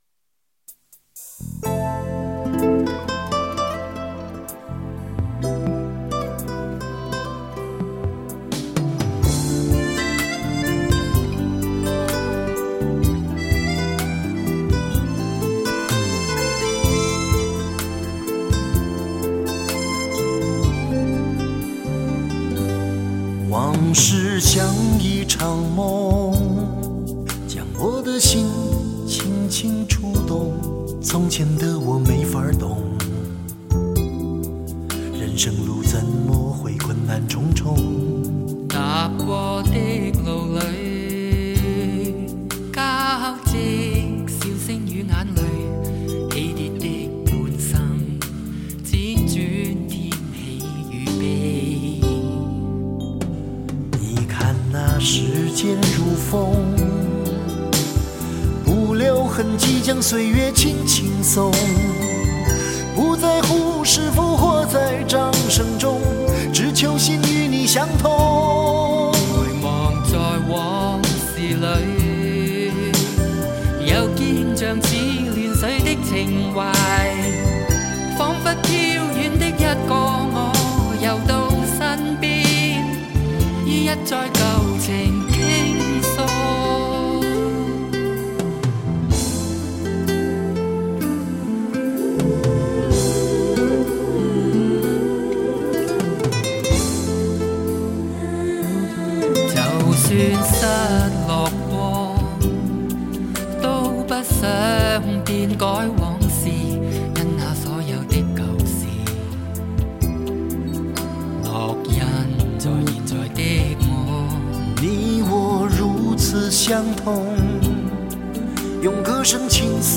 一個粗曠，一個細膩
中間有一段二人分別用國語和粵語的口白對話，聽來更是倍感親切。